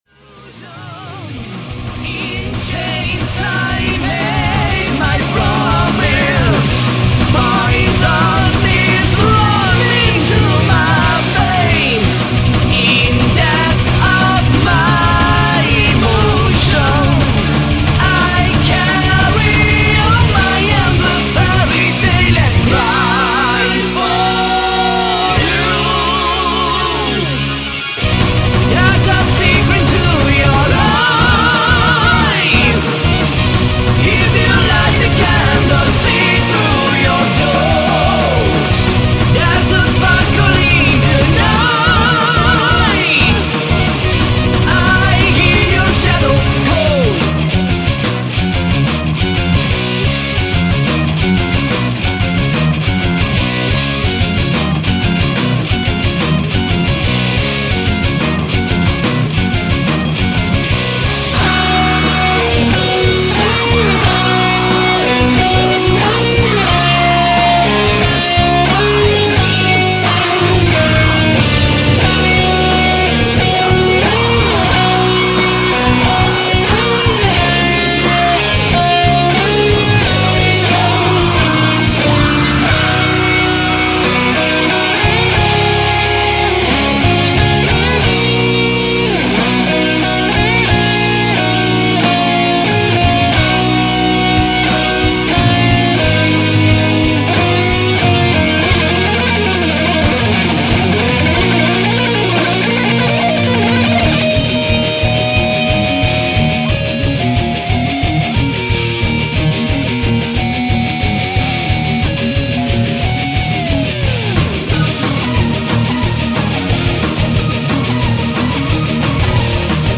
Power Metal